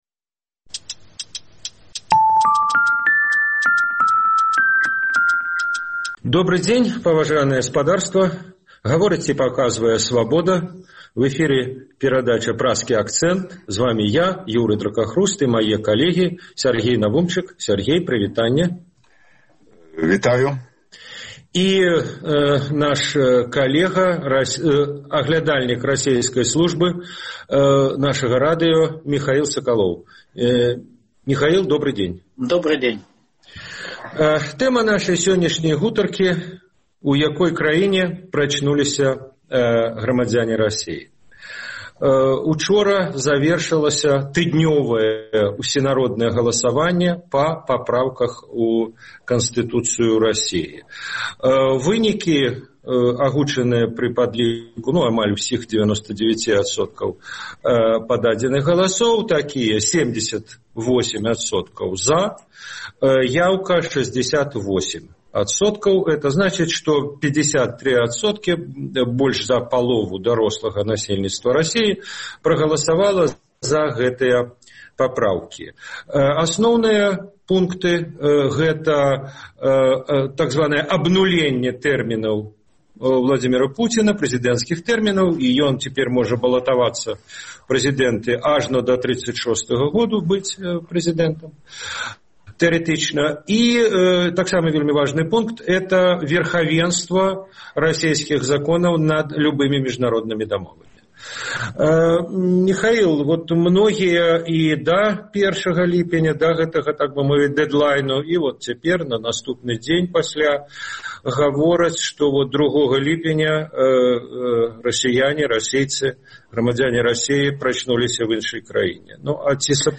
Гэтыя тэмы ў Праскім акцэнце абмяркоўваюць аглядальнікі Свабоды